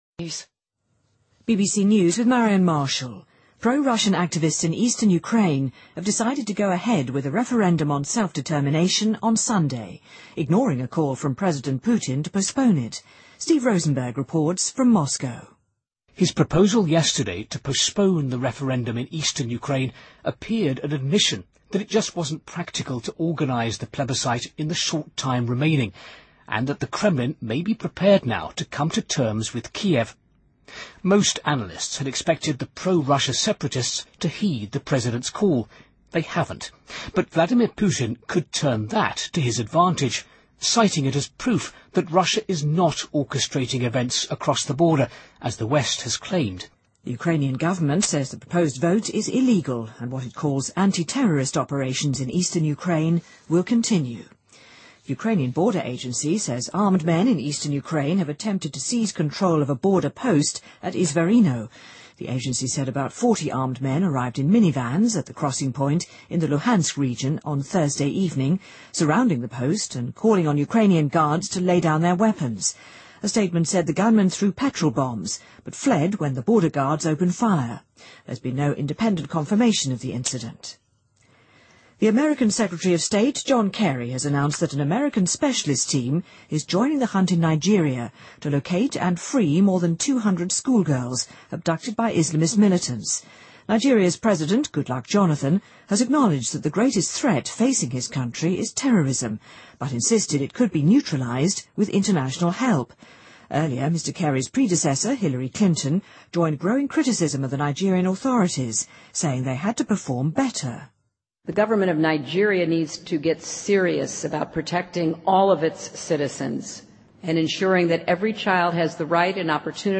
BBC news,南非执政党非国大(ANC)在全国大选中赢得了决定性的胜利